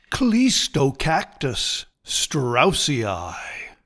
Pronounciation:
Cleese-toe-CAC-tus straus-EE-i